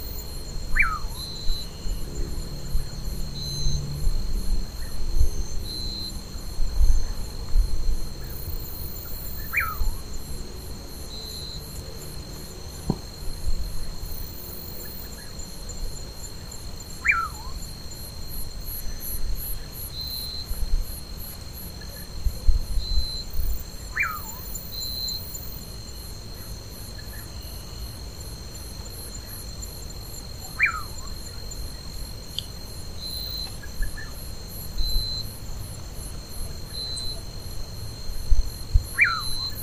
Pauraque (Nyctidromus albicollis)
Life Stage: Adult
Country: Brazil
Location or protected area: Maceio
Condition: Wild
Certainty: Recorded vocal